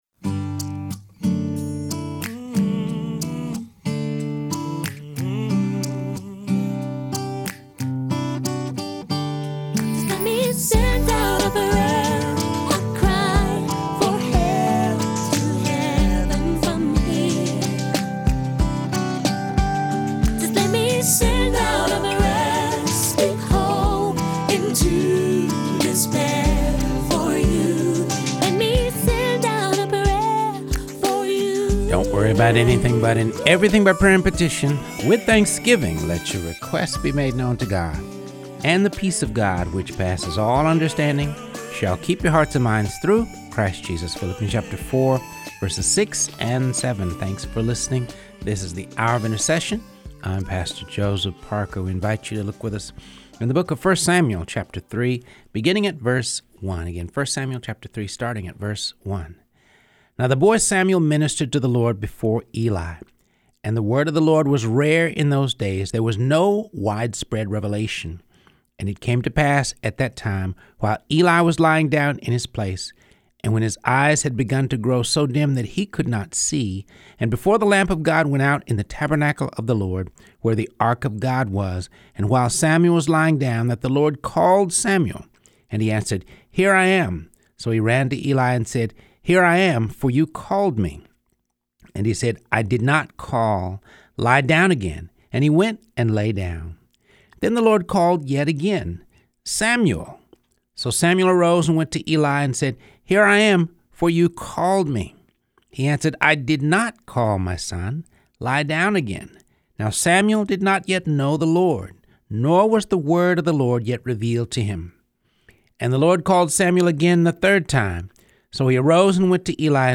reads through the Bible.